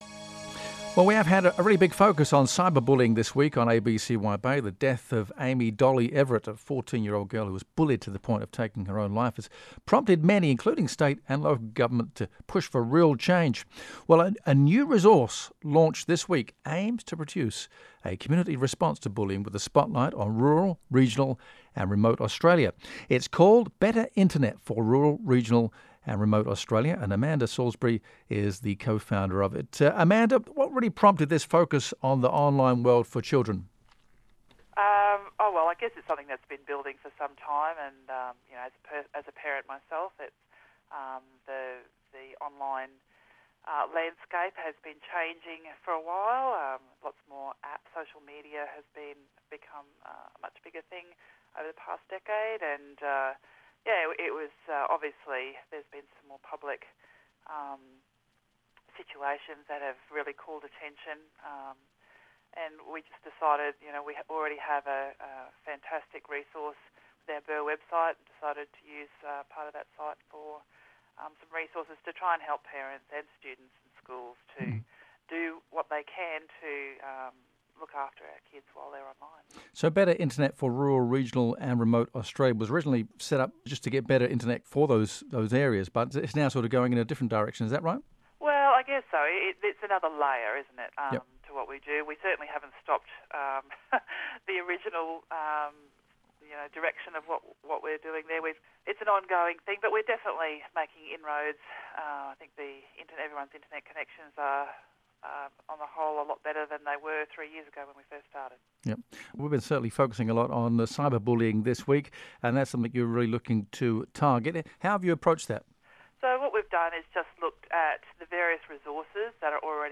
ABC radio interview on BIRRR Cyber Safety pages (Feb, 2018):